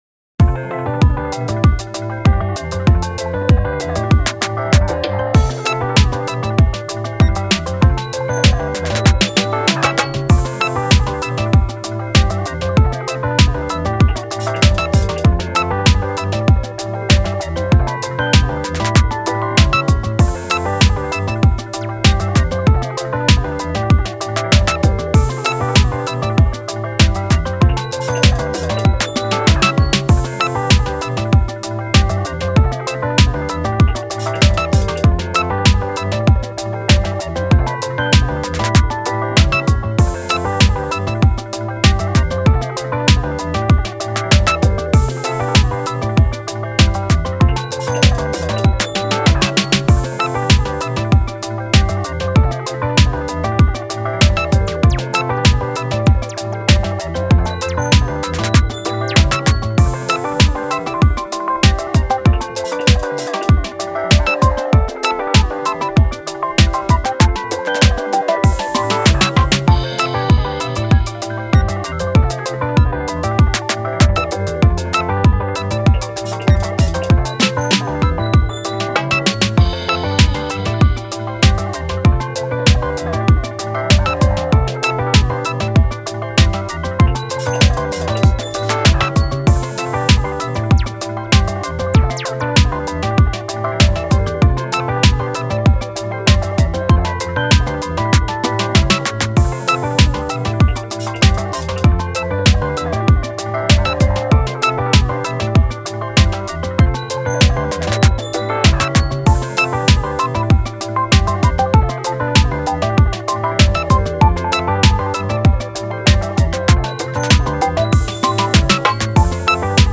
明るい・ポップ